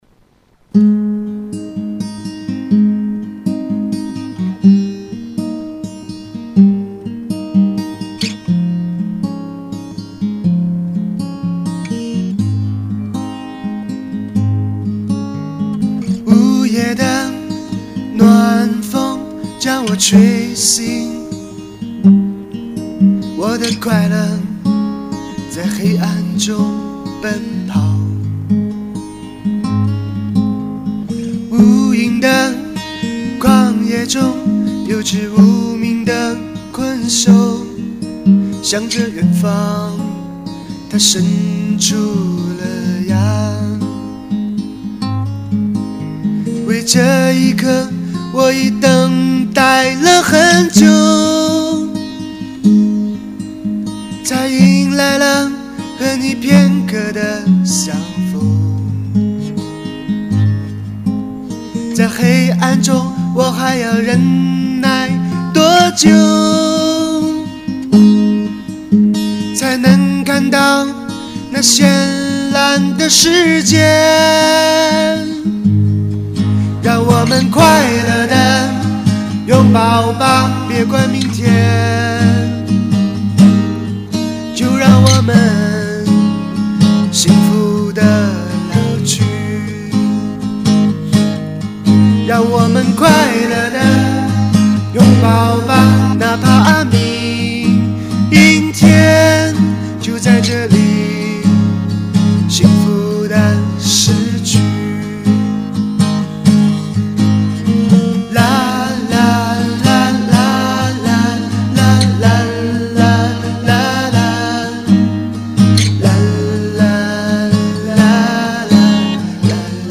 录音并不完美，不过，其实我喜欢的也不是完美，而是唱歌时那种最真实的心情……希望你有感觉